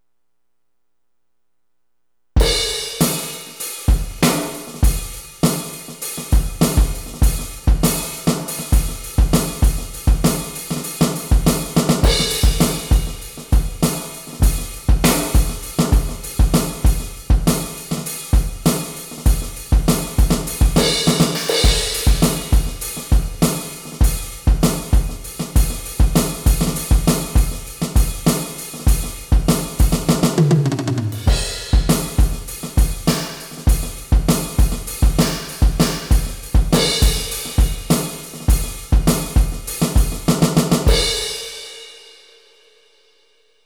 sock_heavybeat_100-02.wav